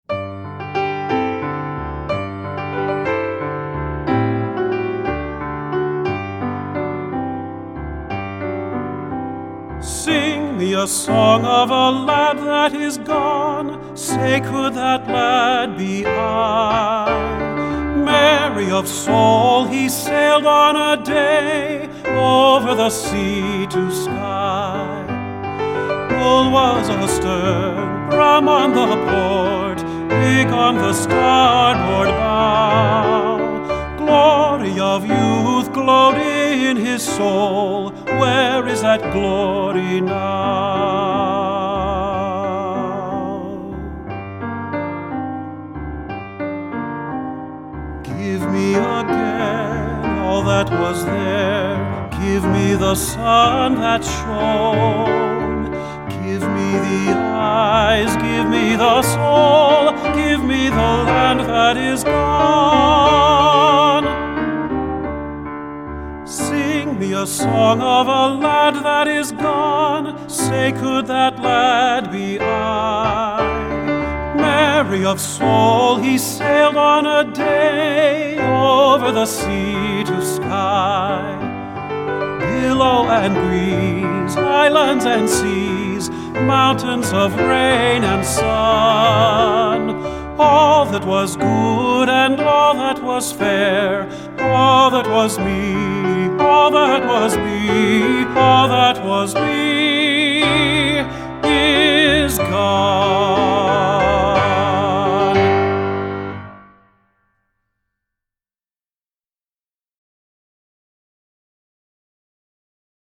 Solos for the Developing Male Voice
Voicing: Medium-High Voice Book and CD